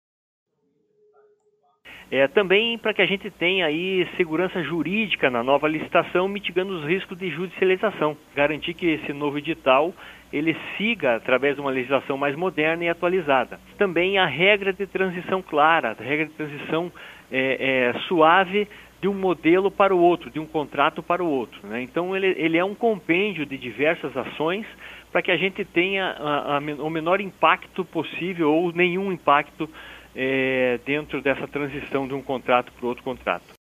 Em entrevista à CBN Curitiba, o presidente da Urbs, Ogeny Pedro Maia Neto, explicou mais sobre os objetivos dessas mudanças, incluindo a atualização da atual lei sobre o transporte coletivo de Curitiba.